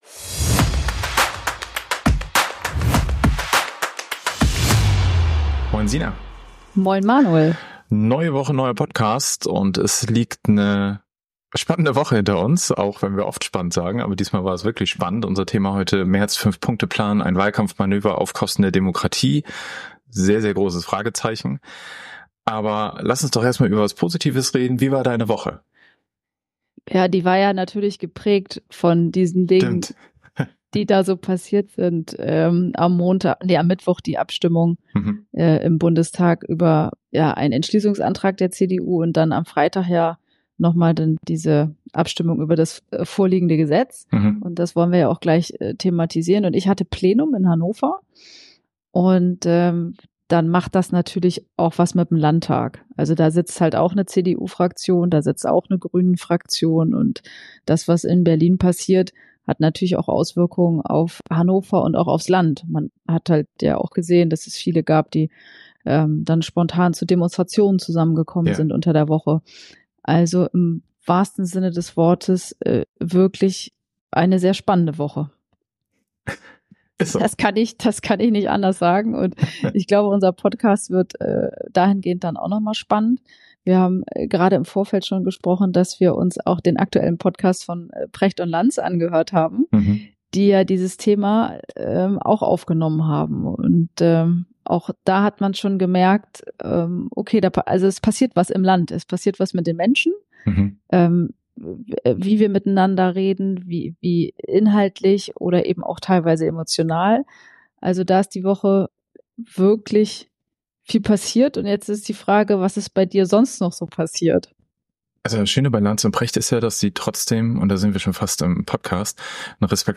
Beschreibung vor 10 Monaten In der 39. Episode von Politik aufs Ohr diskutieren wir Friedrich Merz’ umstrittenen Fünf-Punkte-Plan.